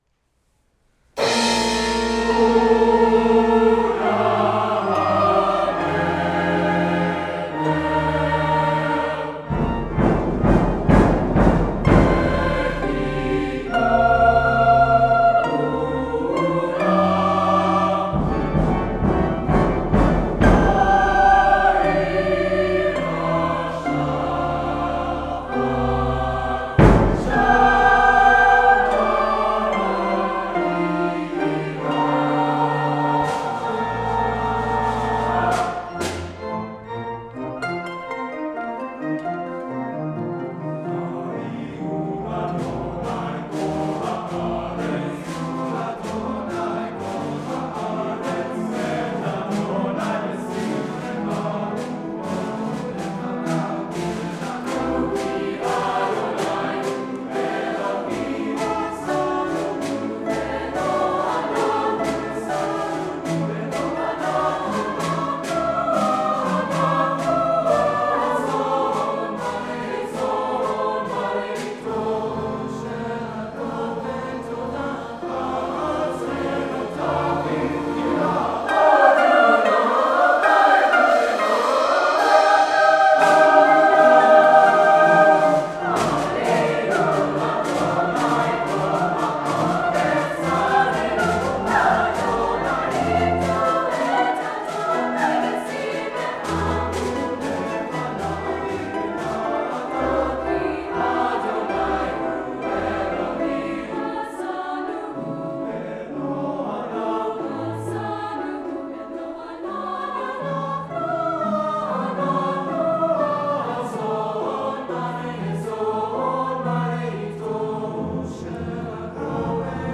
Brookline High School Camerata
Sunday, April 10, 2011 • United Parish, Brookline, MA